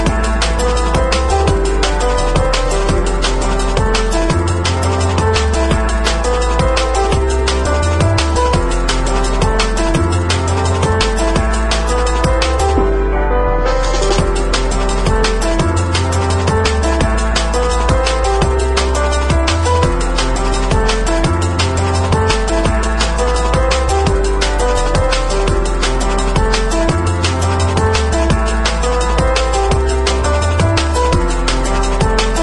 TOP >Vinyl >Drum & Bass / Jungle
TOP > Deep / Liquid